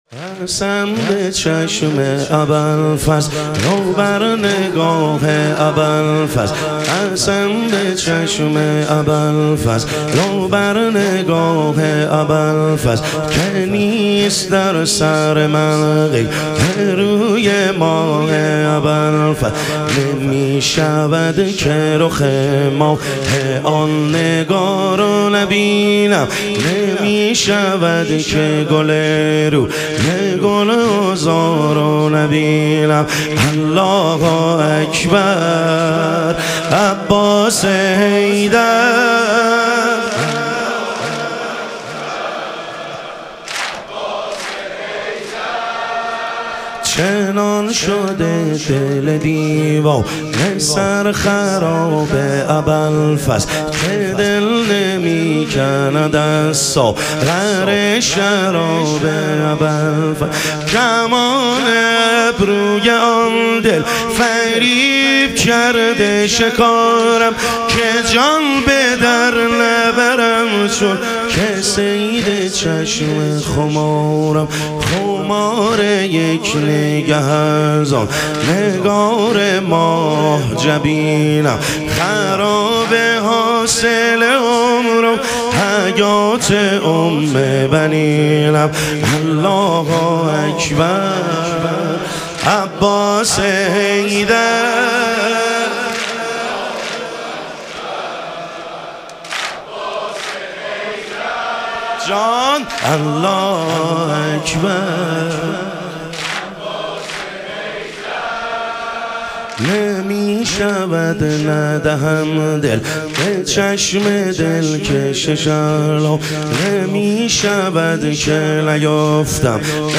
شب شهادت حضرت ام البنین علیها سلام